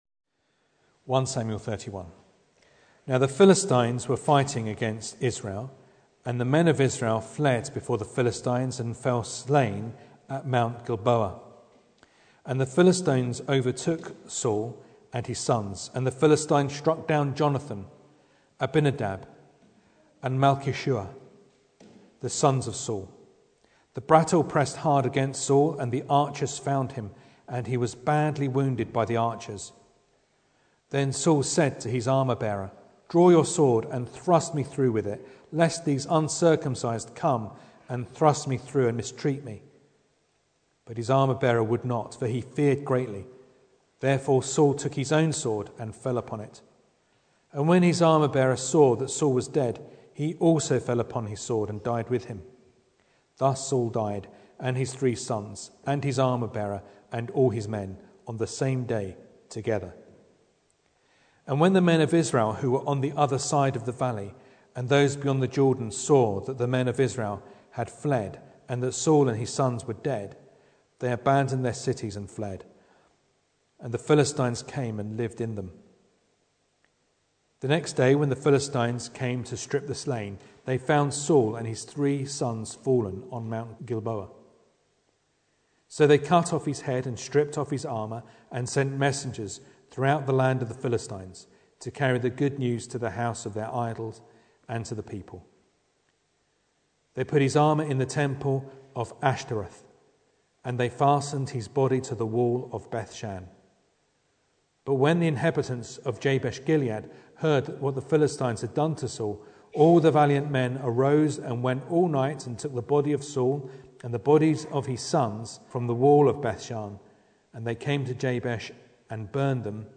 1 Samuel 31 Service Type: Sunday Evening Bible Text